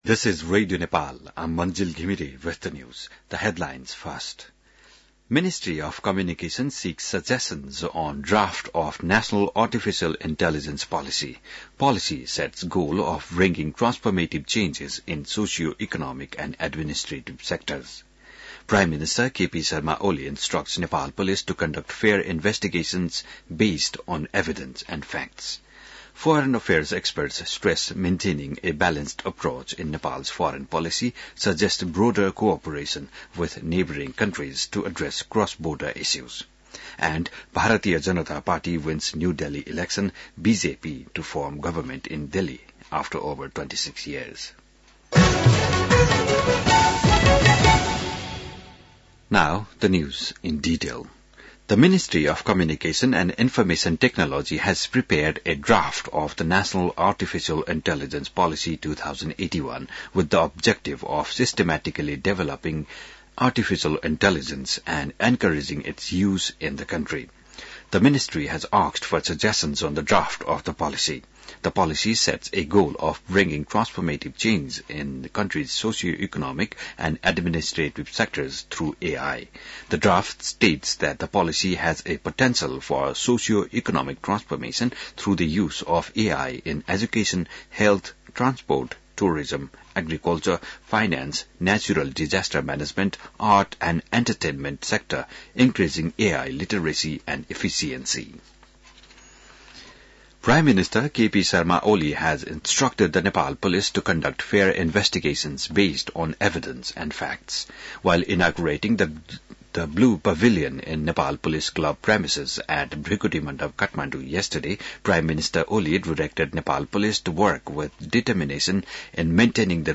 बिहान ८ बजेको अङ्ग्रेजी समाचार : २८ माघ , २०८१